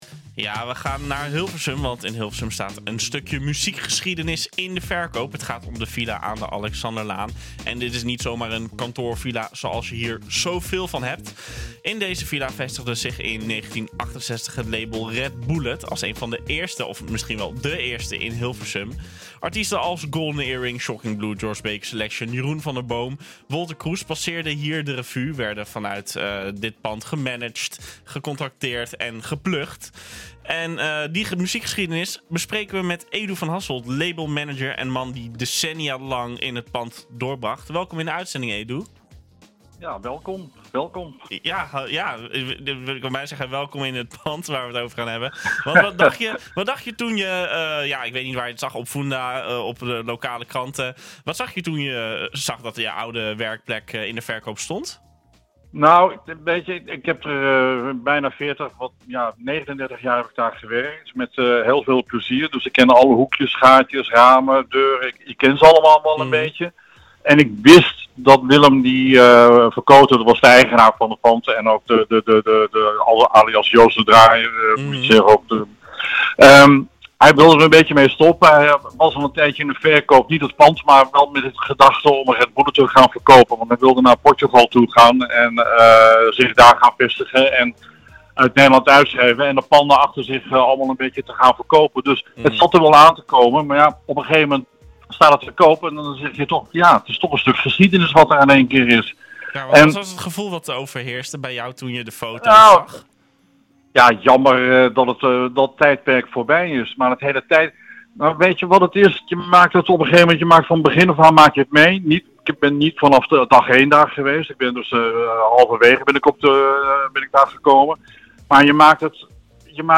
NHGooi is de streekomroep voor Gooi & Vechtstreek.